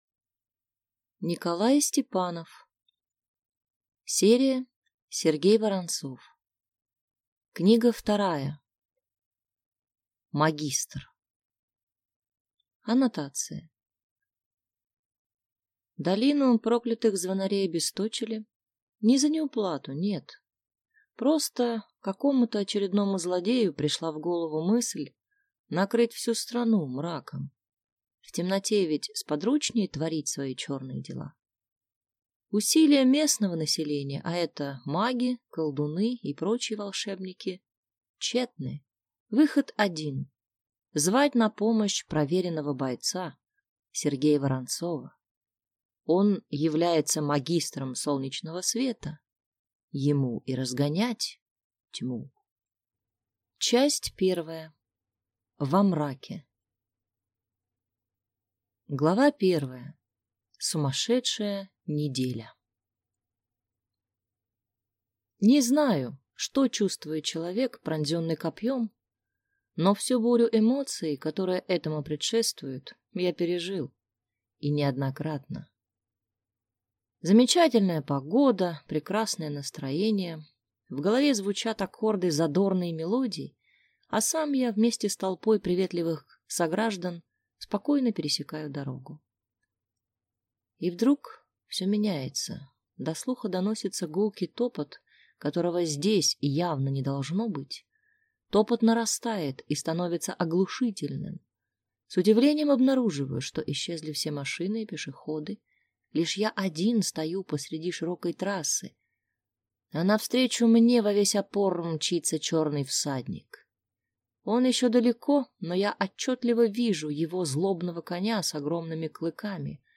Аудиокнига Магистр | Библиотека аудиокниг